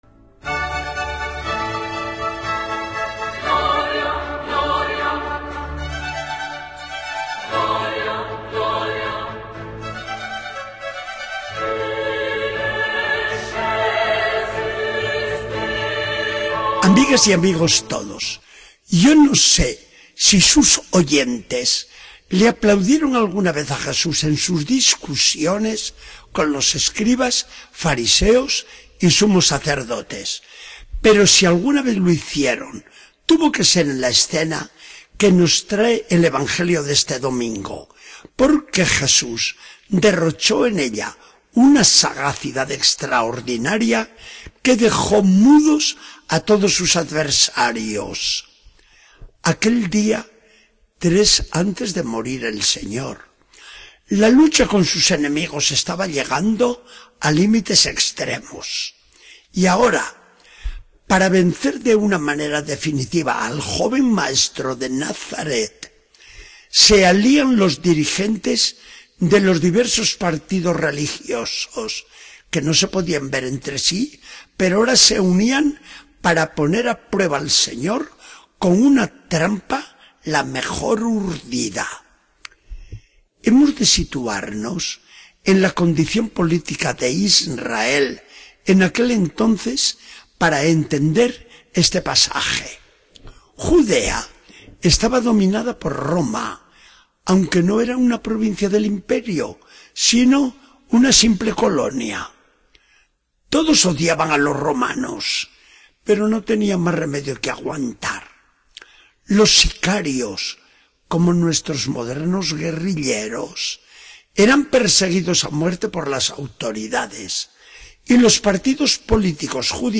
Charla del día 19 de octubre de 2014. Del Evangelio según San Mateo 22, 15-21.